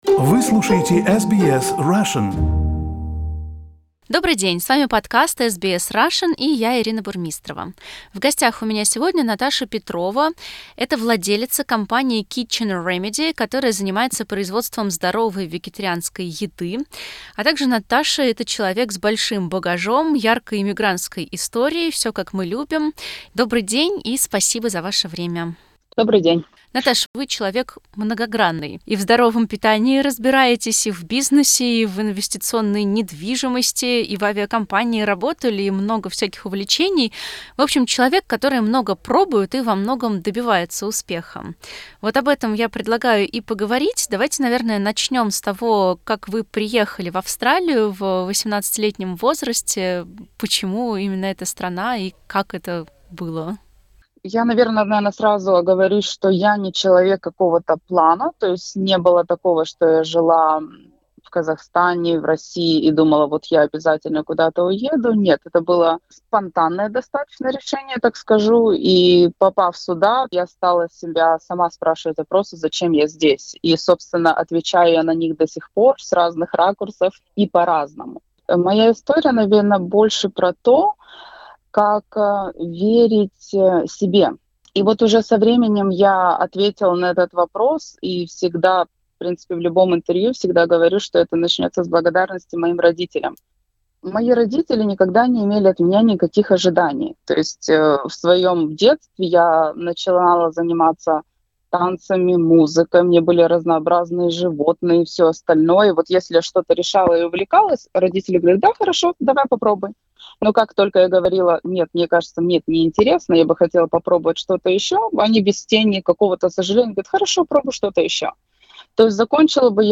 Послушать вдохновляющее интервью можно в плеере наверху страницы.